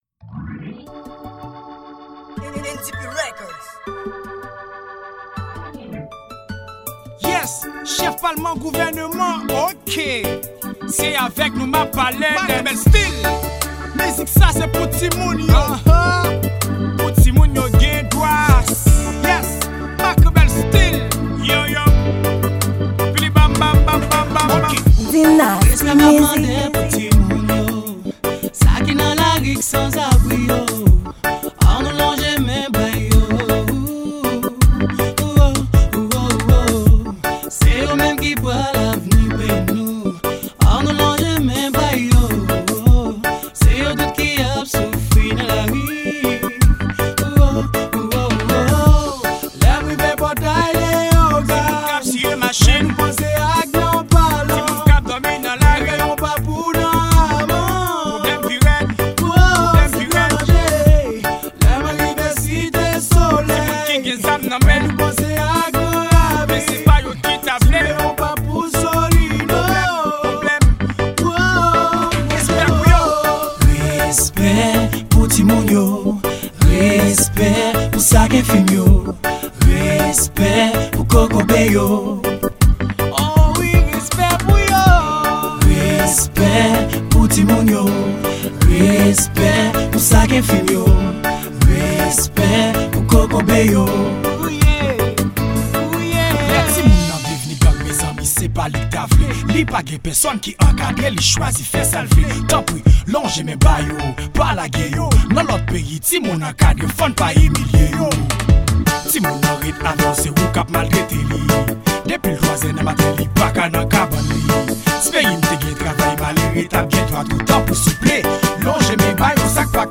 Genre: Rap-Social.